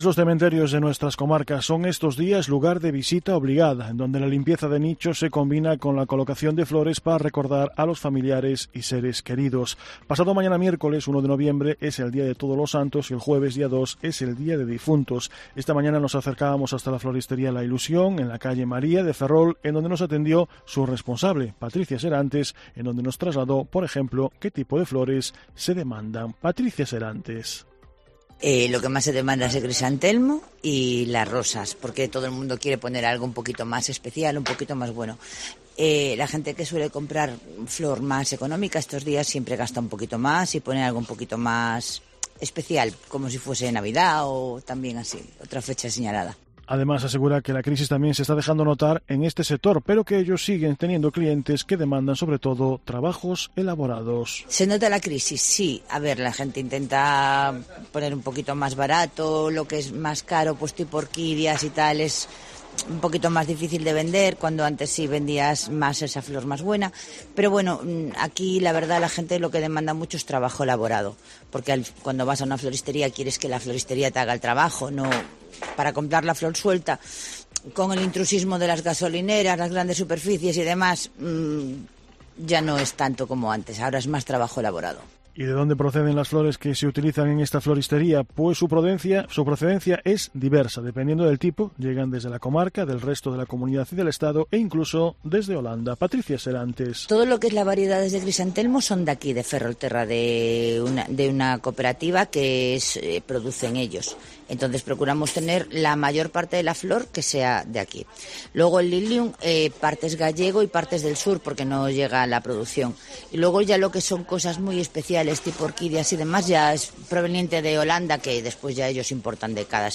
Además, también tuvimos ocasión de recoger algunos testimonios.
Por ejemplo, un hombre detallaba que solo pone flores en “esta época del año, que busca una floristería próxima a su domicilio y que no tiene una preferencia en cuanto a flores”.
Otra mujer detallaba que aparte de tener flores todo el año y “en esta época le gusta colocar una decoración especial”, en donde en su caso apuesta por las orquídeas.